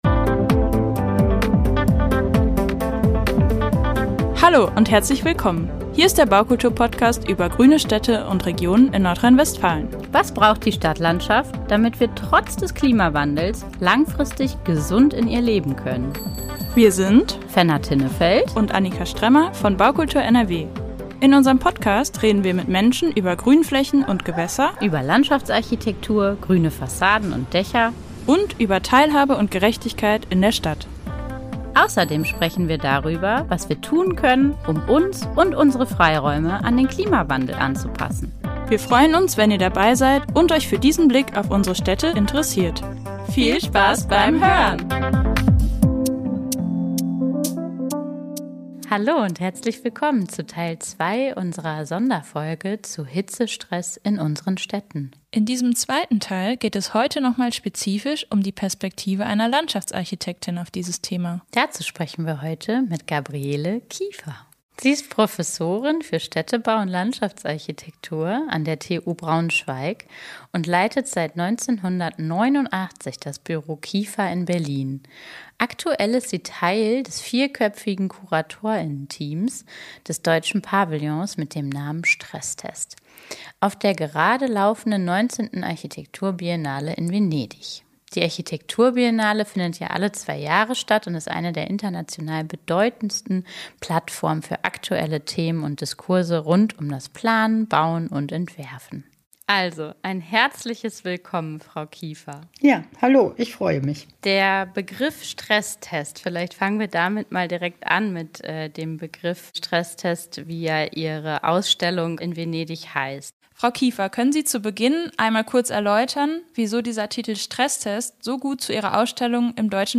Hitze als Stresstest: Ein Gespräch